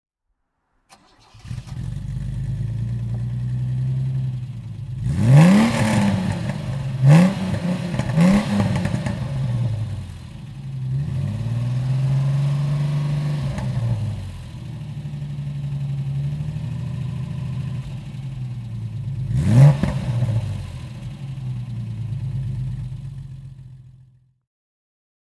Ford Capri 2.8 Injection (1982) - Starten und Leerlauf